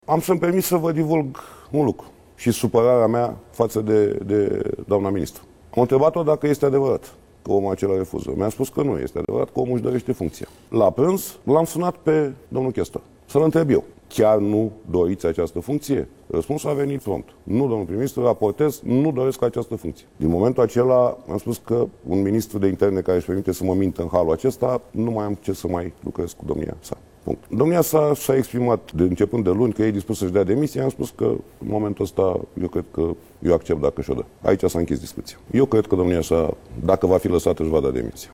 Premierul a declarat, într-o emisiune tv, că va accepta demisia ministrului Internelor, dacă ar recurge la un asemenea gest în contexul scandalului generat de cazul poliţistului arestat, miercuri, pentru pedofilie.